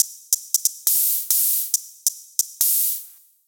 Drexal Hats 138bpm.wav